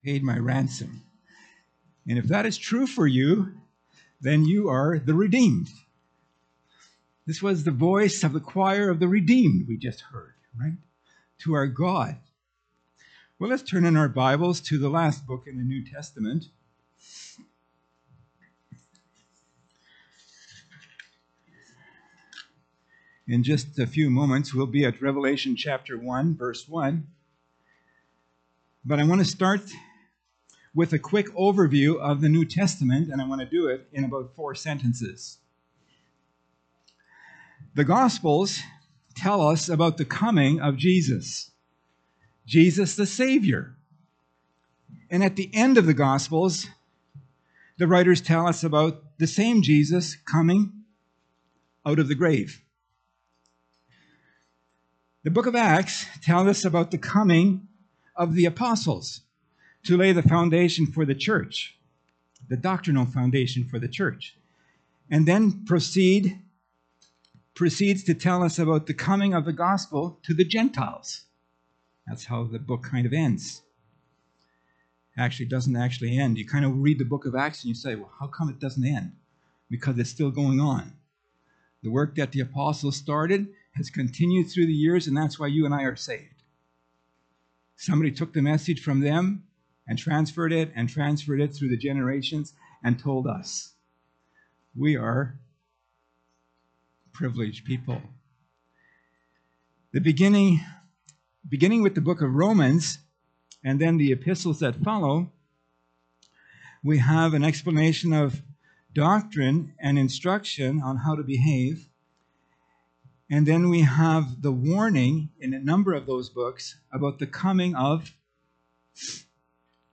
Pulpit Sermons Key Passage: Revelation 1:1